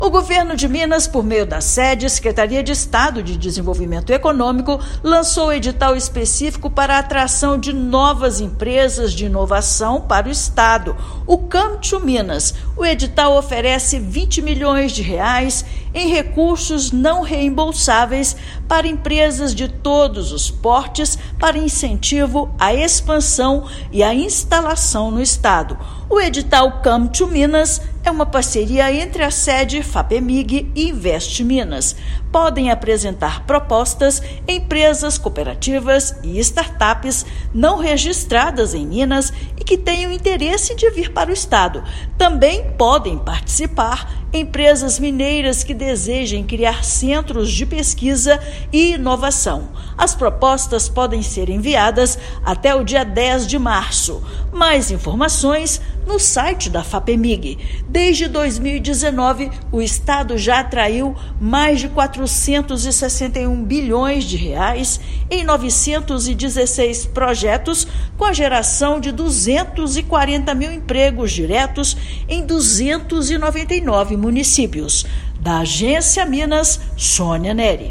Oportunidades do Governo de Minas, como o edital para impulsionar negócios, o estado se consolida como terra de negócios e oportunidades. Ouça matéria de rádio.